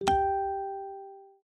Звуки фейсбука
Музыкальные сигналы для Facebook